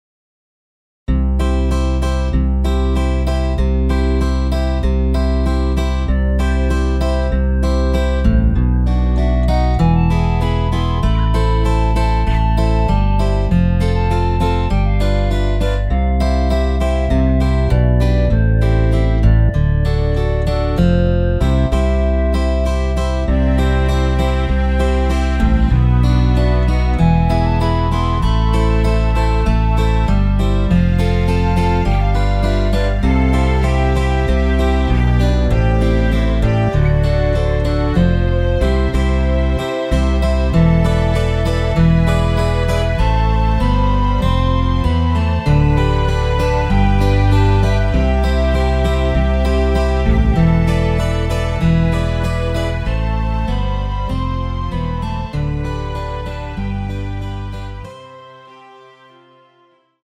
원키에서(+7)올린 멜로디 포함된 MR입니다.
앞부분30초, 뒷부분30초씩 편집해서 올려 드리고 있습니다.
중간에 음이 끈어지고 다시 나오는 이유는